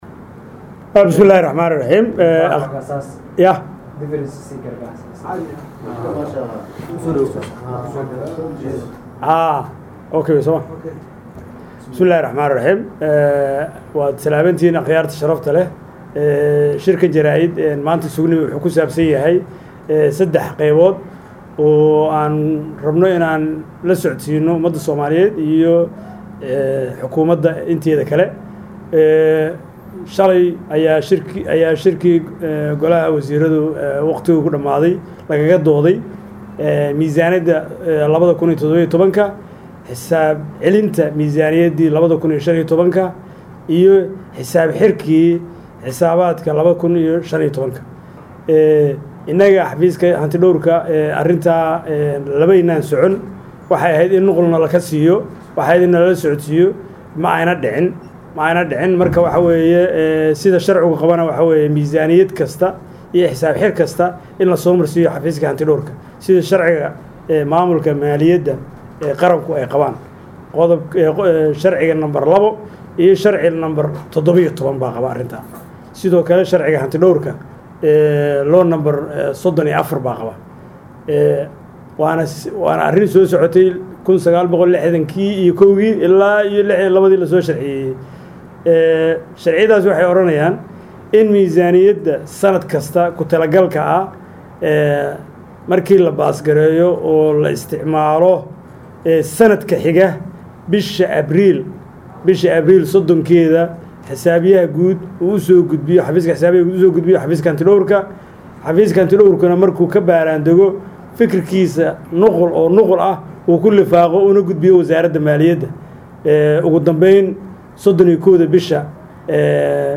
SHIR-JARAAID-HANTI-DHOWRKA-GUUD-EE-QARANKA..mp3